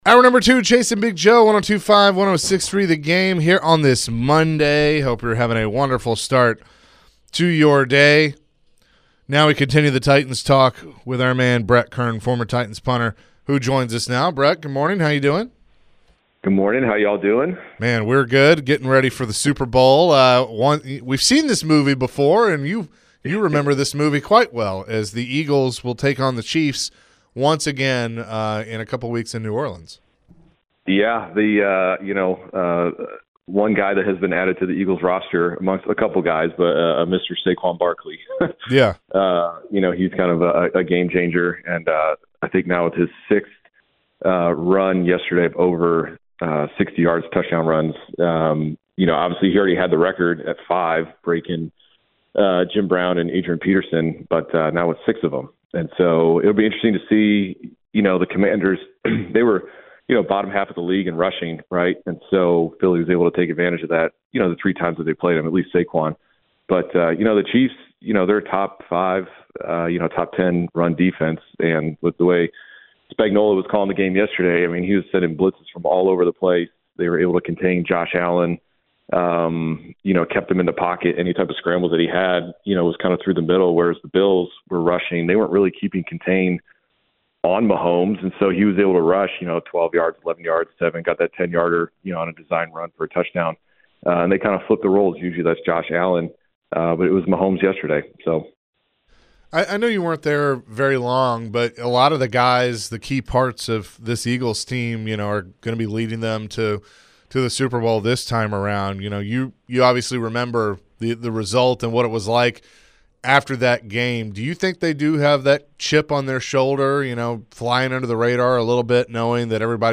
Later in the hour, the guys answered some calls and texts about the NFL Playoffs and more.